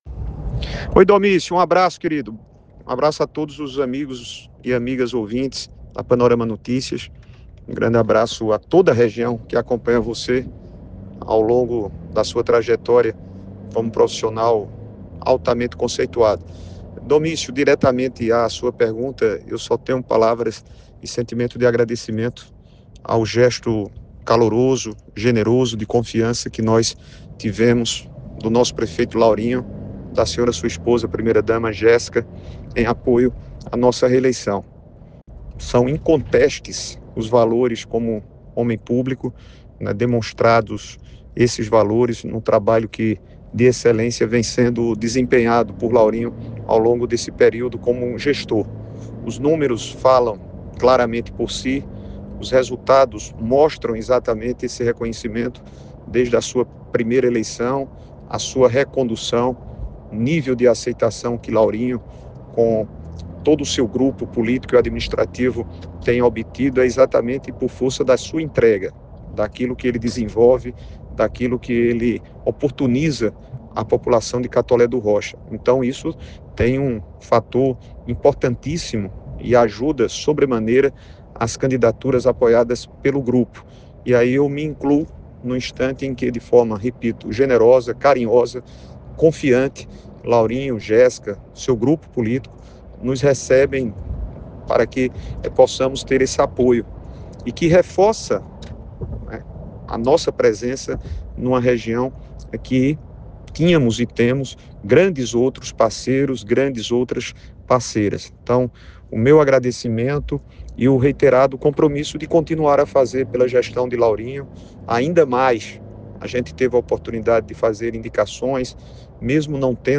O senador Veneziano Vital do Rêgo, pré-candidato à reeleição, participou ontem segunda-feira (30) do programa Panorama Notícias, onde comentou o cenário político e destacou o fortalecimento de sua base no Sertão paraibano, especialmente no município de Catolé do Rocha.